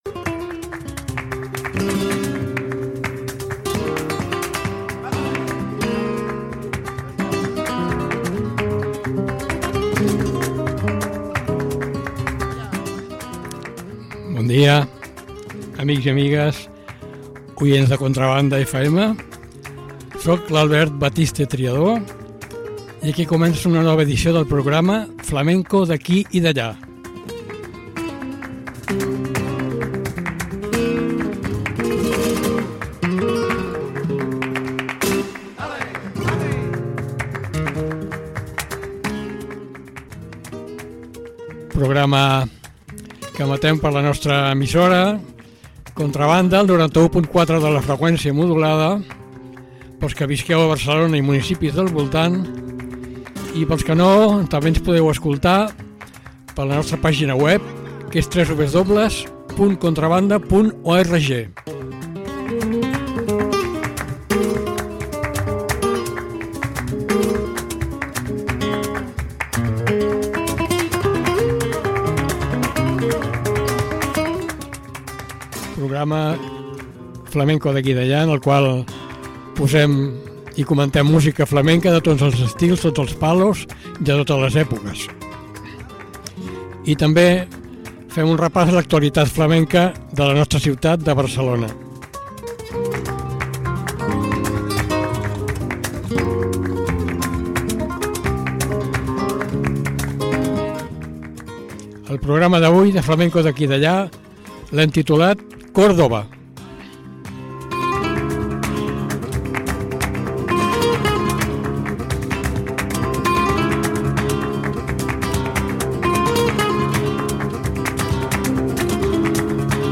Música andalusí.
Serranas.
Sevillanas.
Farruca.